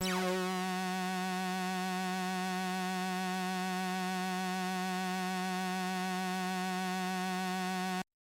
标签： F4 MIDI音符-66 赤-AX80 合成器 单票据 多重采样
声道立体声